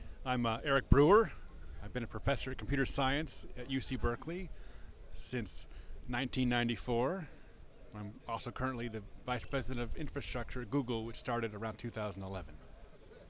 English: Eric Brewer introducing himself
Eric_Brewer_(scientist)_-_voice_-_en.flac